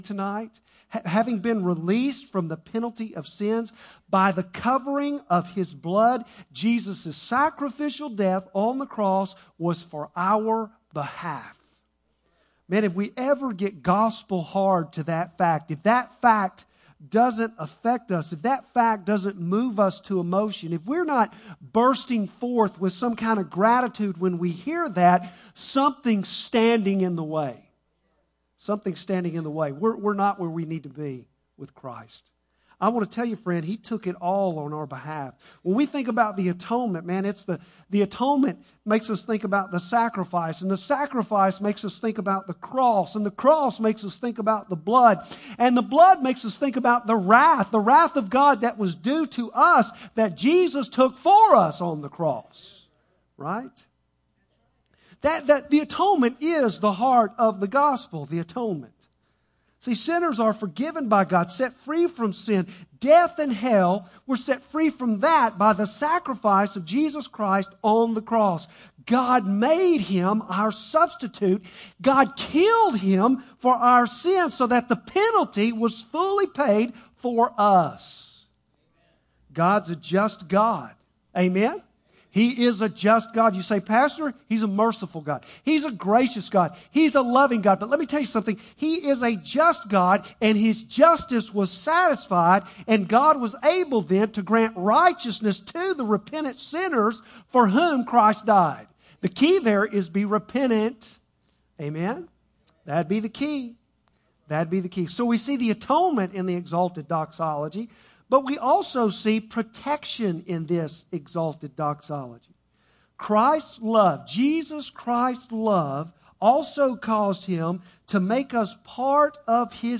Bible Text: Revelation 1:5-8 | Preacher